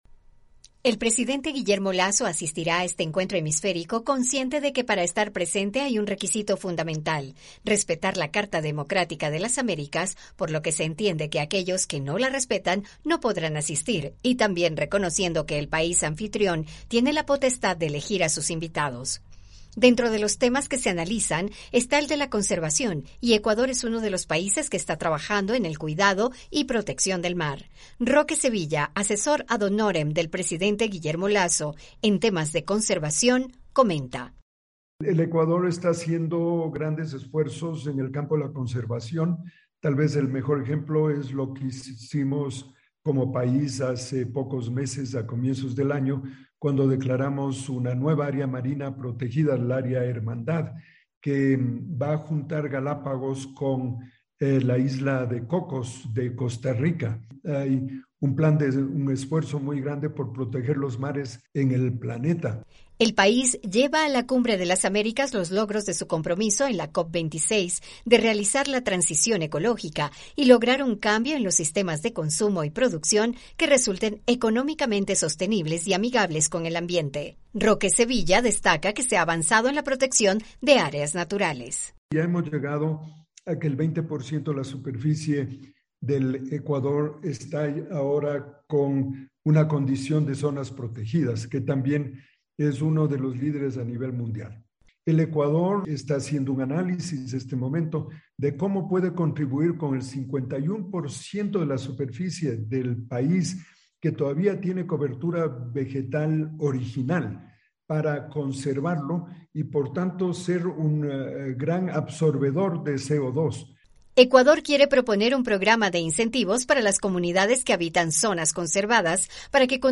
Lasso, dijo a la VOA que además quiere estrechar las relaciones con Estados Unidos a través de un Tratado de Libre Comercio.